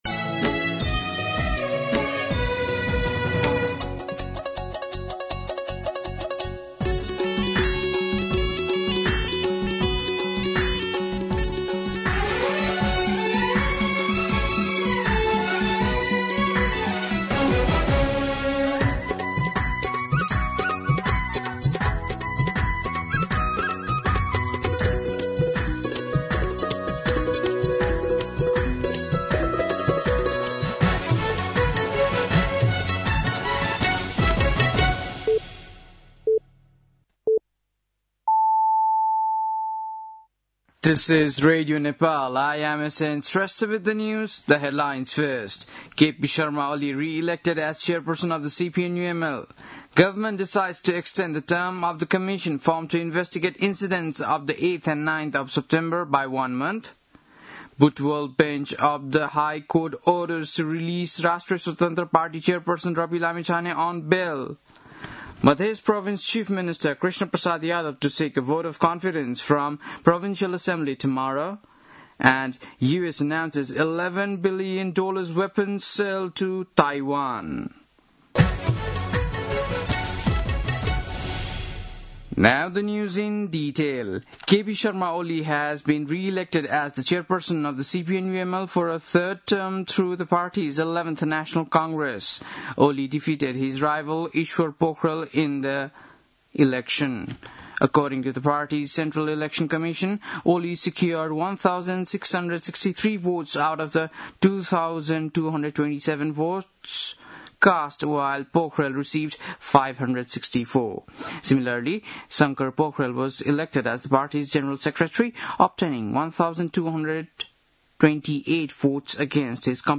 बेलुकी ८ बजेको अङ्ग्रेजी समाचार : ३ पुष , २०८२
8-pm-news-9-3.mp3